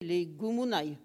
Localisation Barre-de-Monts (La)
Catégorie Locution